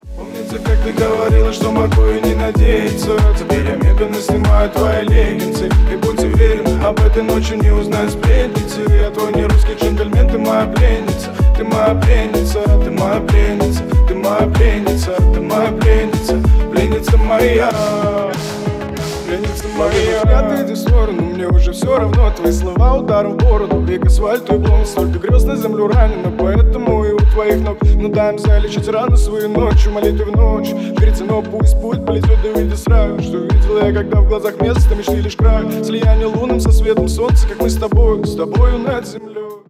Ремикс
Рэп и Хип Хоп